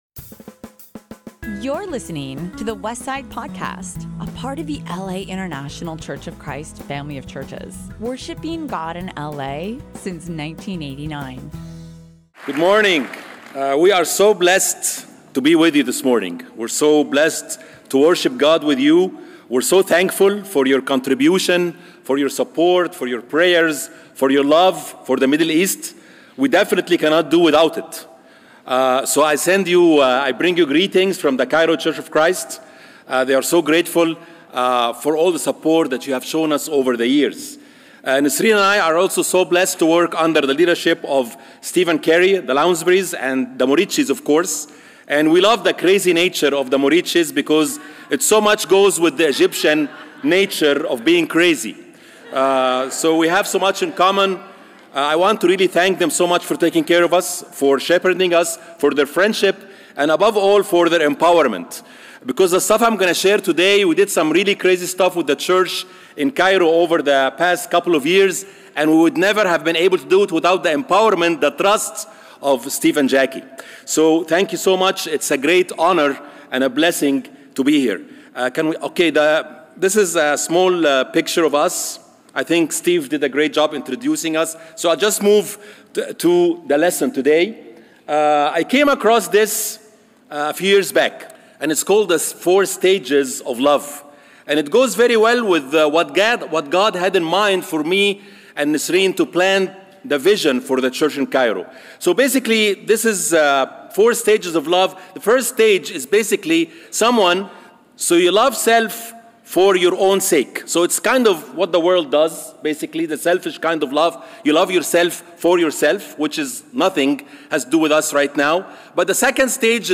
Past Sermons | The Westside Church - Los Angeles, CA